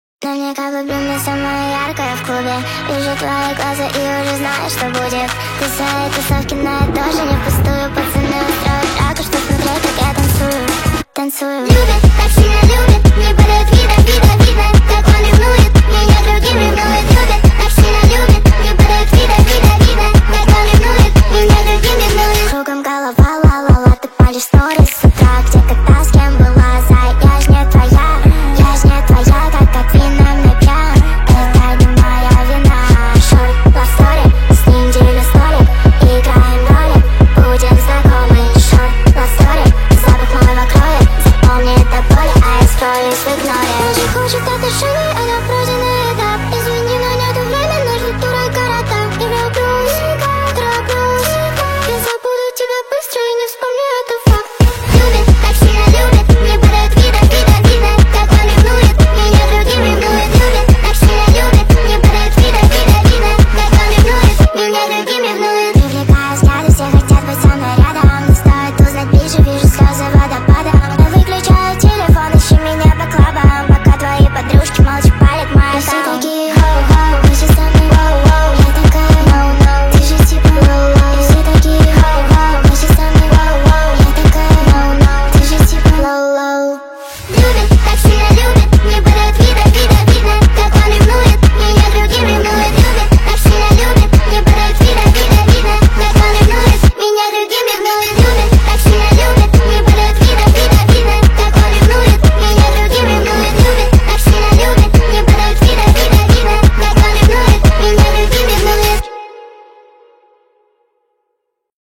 Speed up tiktok remix version